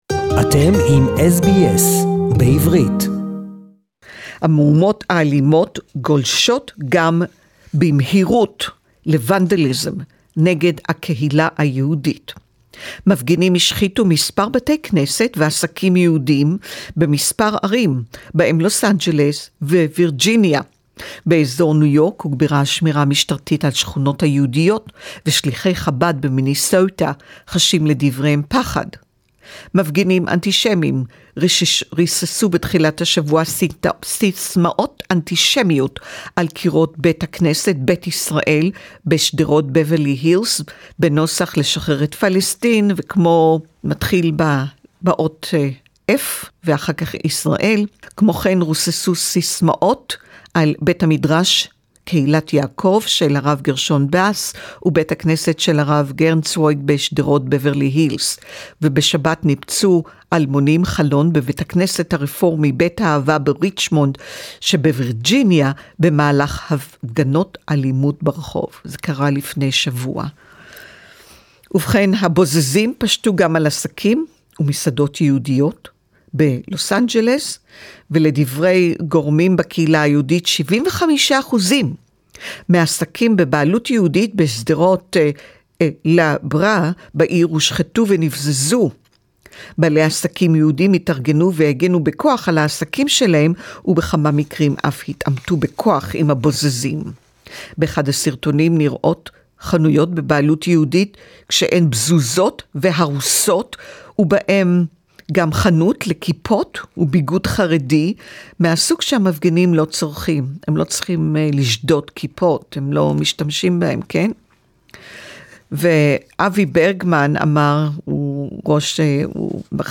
Exploitation of George Floyd death by anti-Semitic & anti-Israel groups, special report, Hebrew